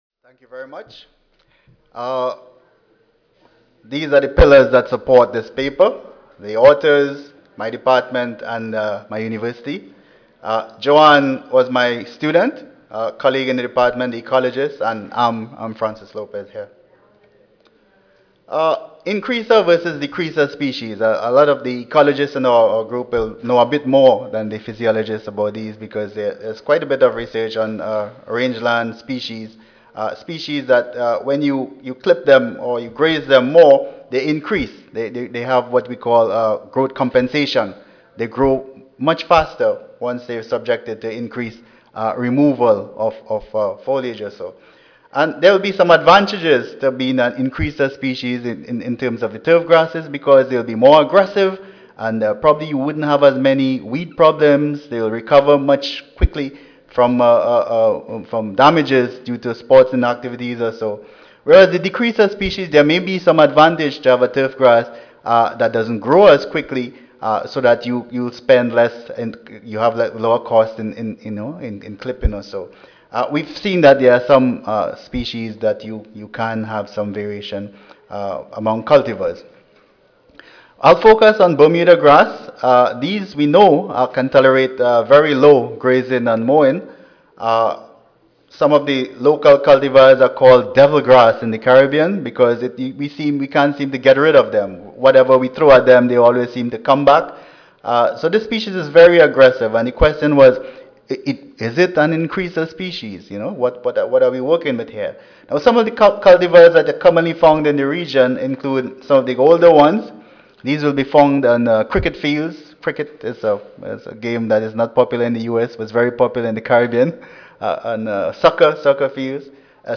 The University of the West Indies Audio File Recorded presentation